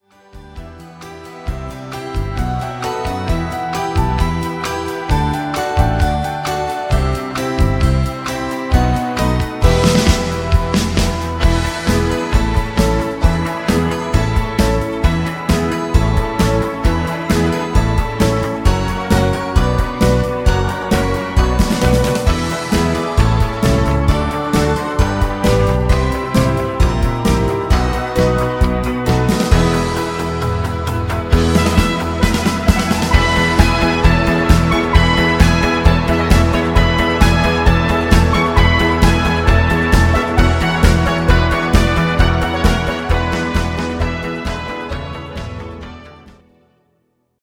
Version 2 enrichie en cordes